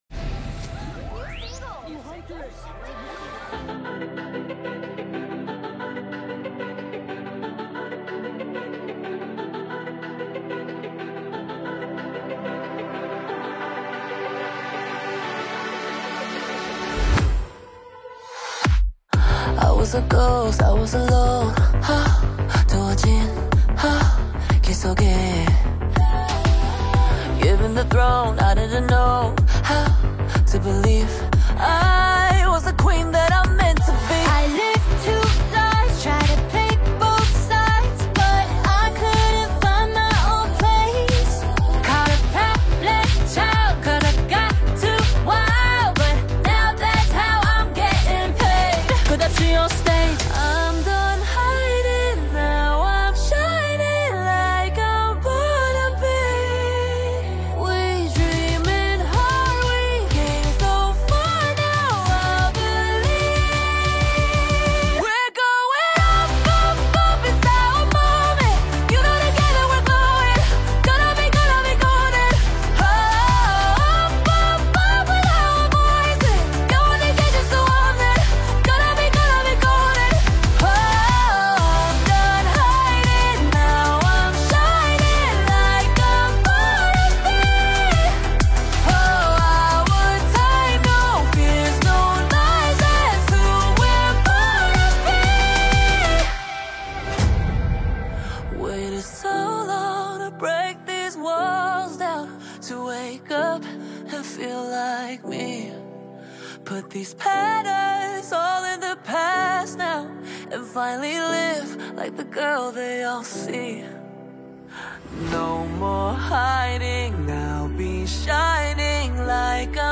K-pop viral song